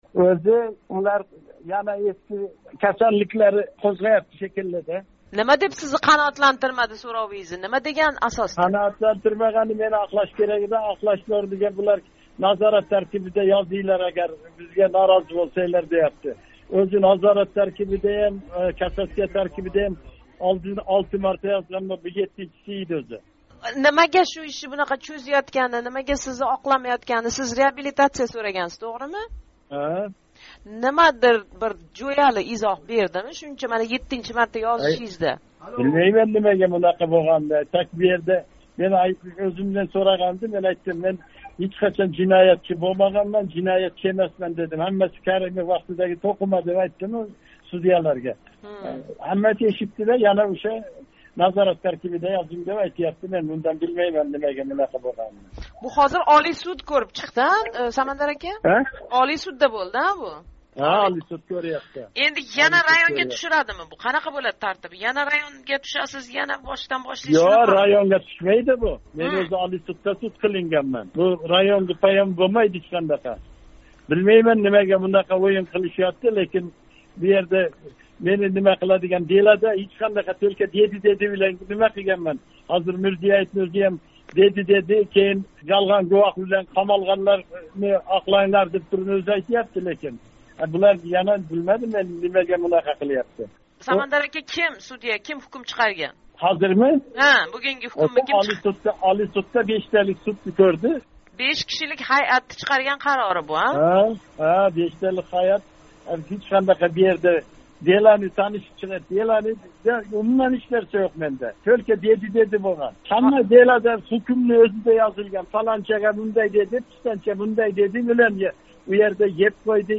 Самандар Қўқонов билан 10 июль кунги ҳукм ҳақида суҳбат